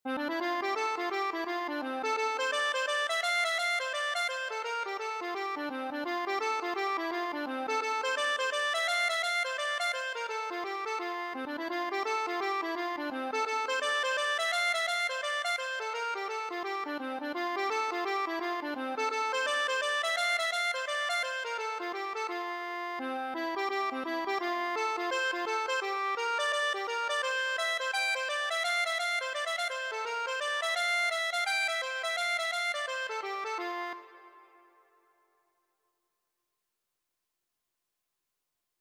4/4 (View more 4/4 Music)
F major (Sounding Pitch) (View more F major Music for Accordion )
Accordion  (View more Easy Accordion Music)
Traditional (View more Traditional Accordion Music)